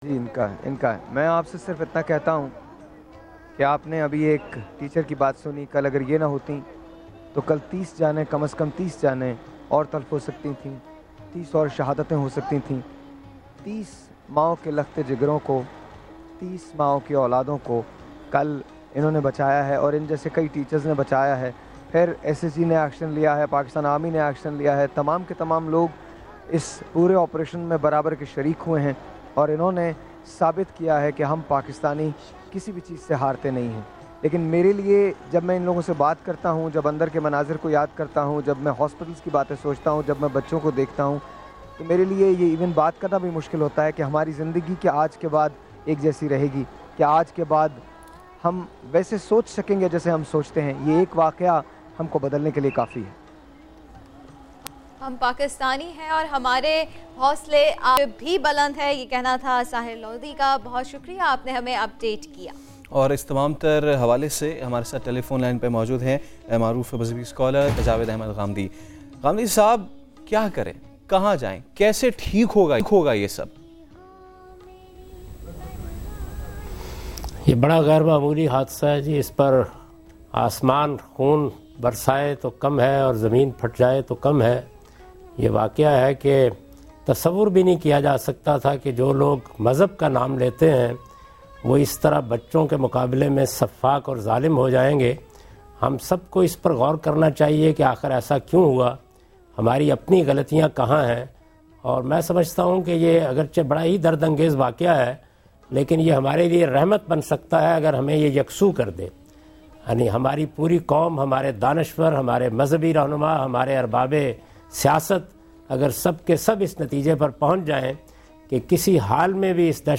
Javed Ahmad Ghamidi expresses his views on SAMAA NEWS about Massacre in Army Public School Peshawar. 16/12/14